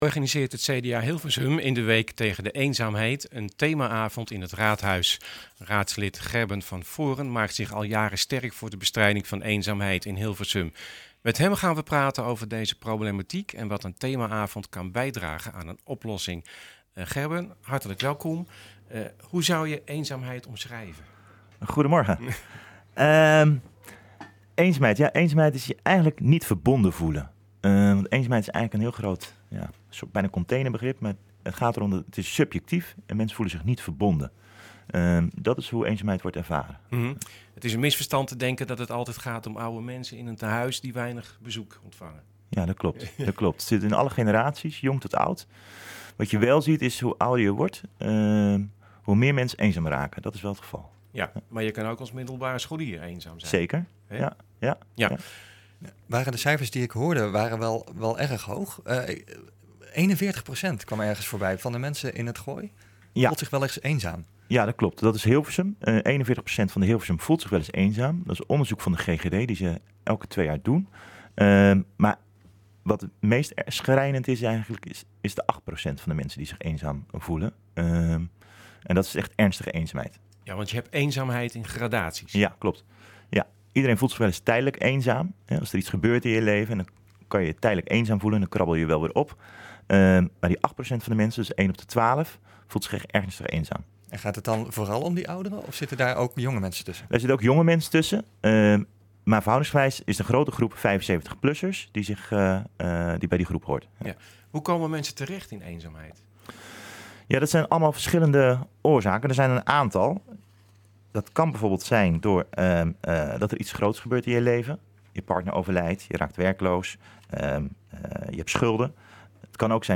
Op 1 oktober organiseert het CDA Hilversum in de Week tegen Eenzaamheid een thema avond in het Raadhuis. Raadslid Gerben van Voorden maakt zich al jaren sterk voor de bestrijding van eenzaamheid in Hilversum. Hij was bij ons te gast om te praten over deze problematiek en wat een thema avond kan bijdragen aan een oplossing.